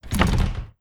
door sounds
blocked1.wav